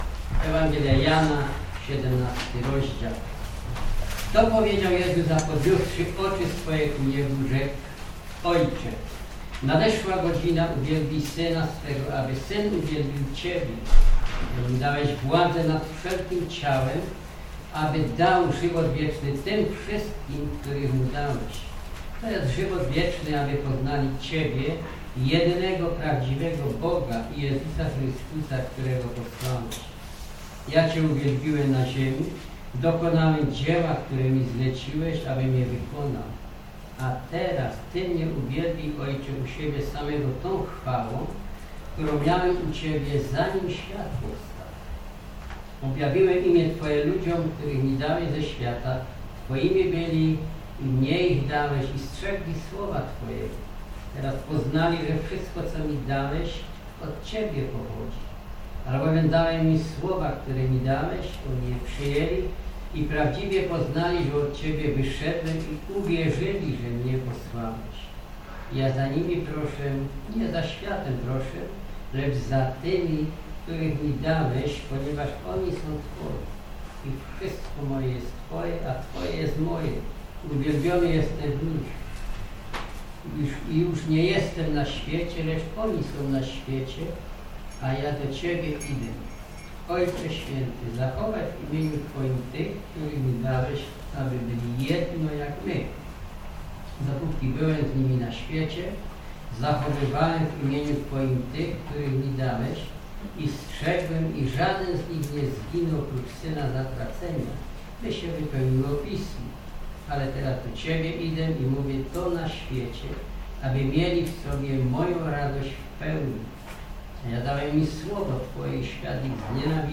Kazania